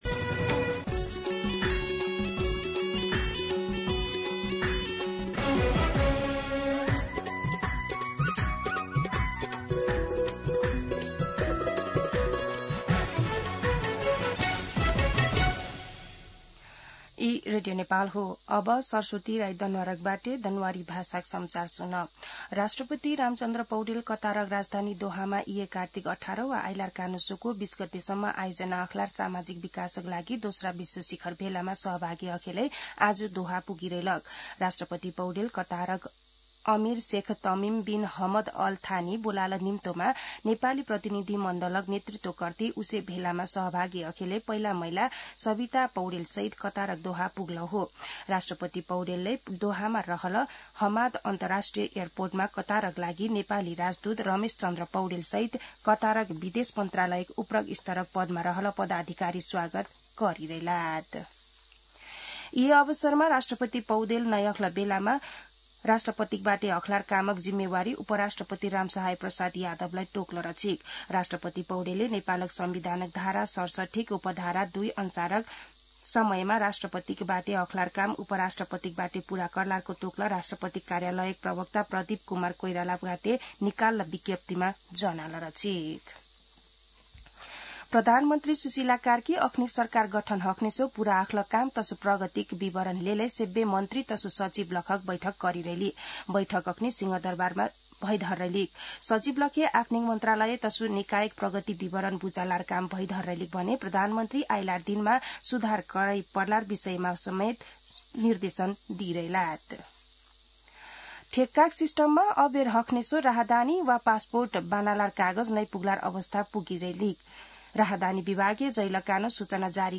दनुवार भाषामा समाचार : १७ कार्तिक , २०८२
Danuwar-News-7-17.mp3